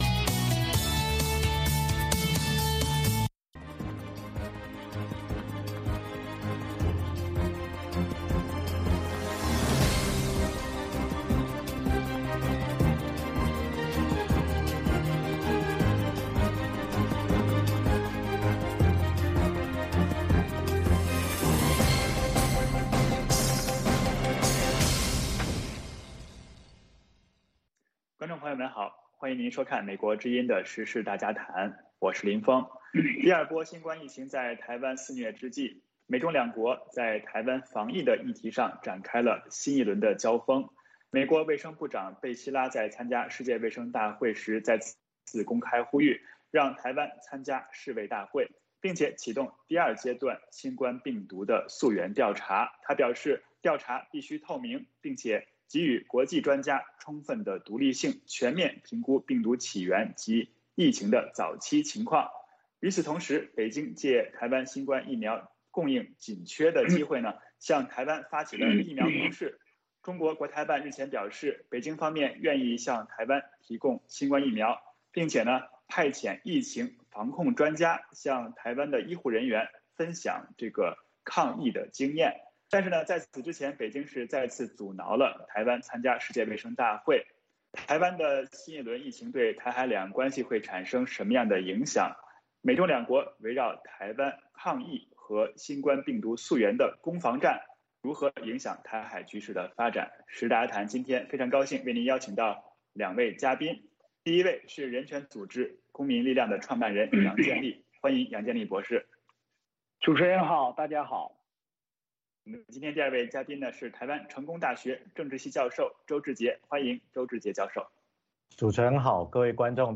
嘉宾：人权组织“公民力量”创办人杨建利